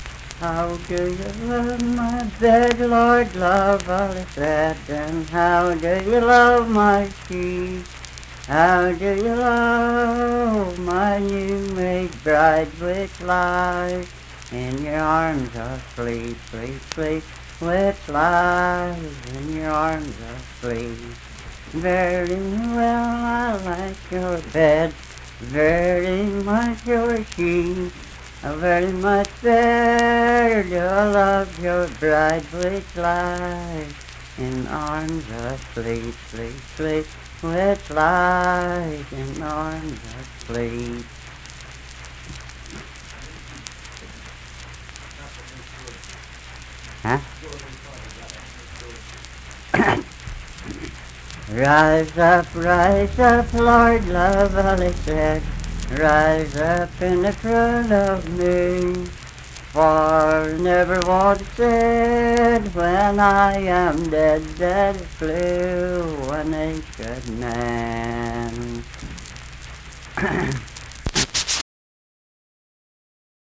Unaccompanied vocal music performance
Voice (sung)